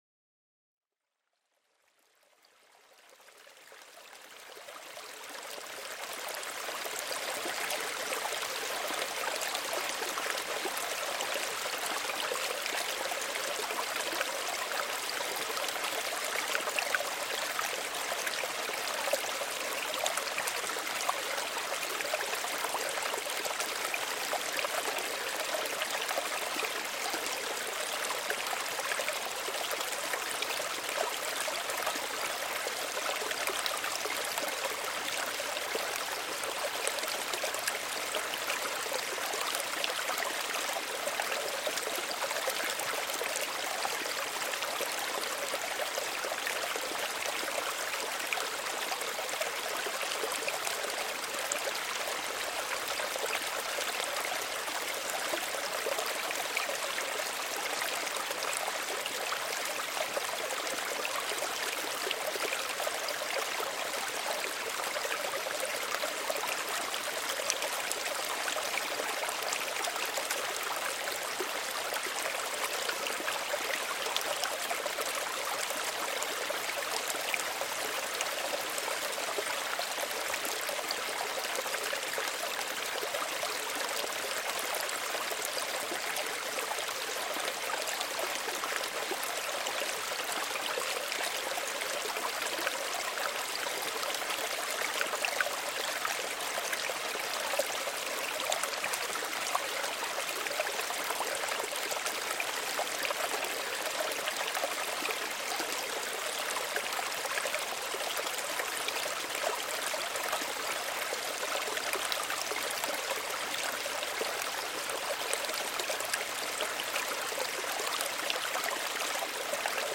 RUHE-PROGRAMMIERUNG: Bach-Geflüster mit Steinenklängen